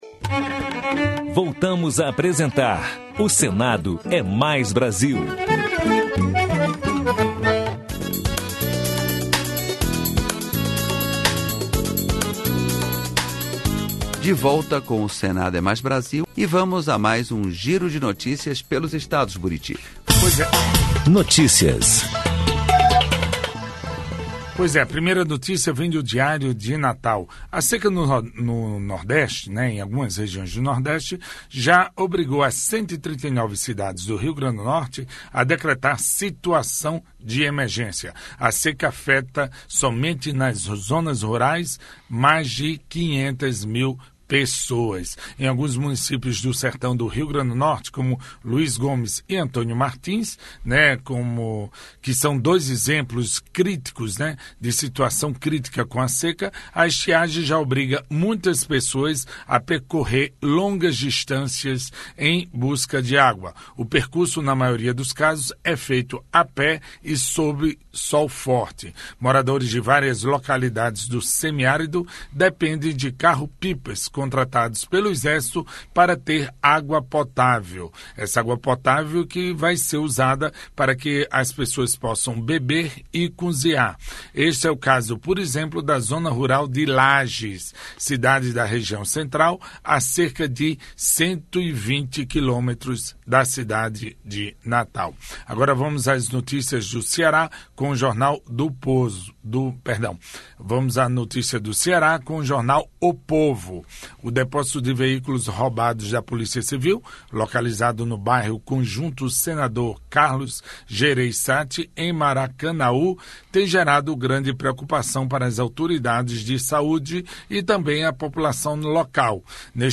O Senado é Mais Brasil - 2º bloco Notícias: giro pelos estados Minuto Cultural: Rio Branco (AC) Viver da Terra: Projeto criminaliza o uso ilegal de agrotóxicos. Entrevista com a senadora Ana Amélia (PP-RS), relatora da proposta